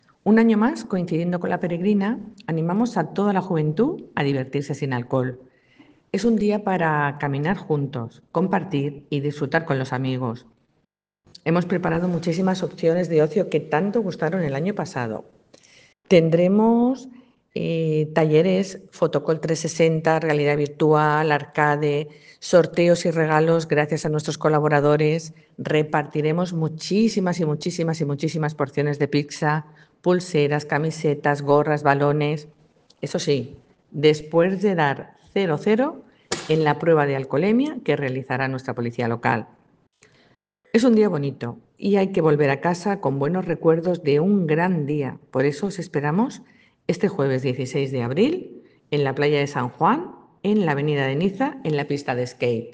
Corte de voz de Begoña León, concejal de Bienestar Social de Alicante